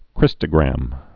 (krĭstə-grăm)